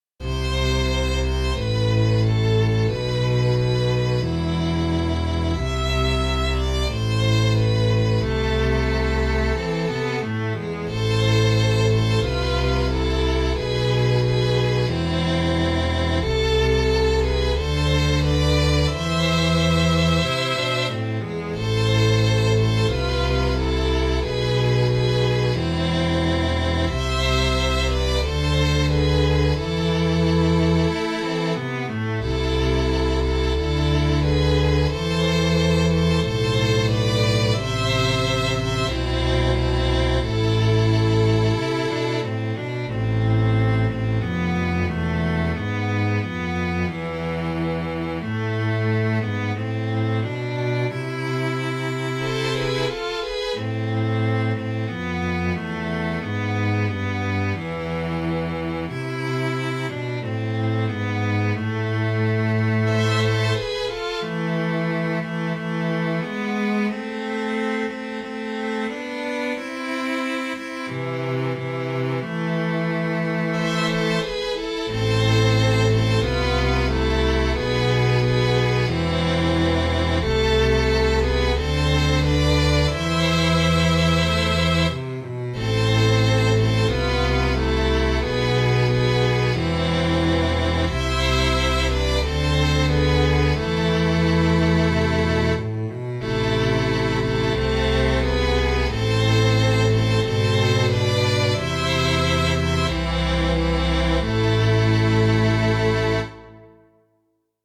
・「鎌倉変奏曲」（弦楽合奏）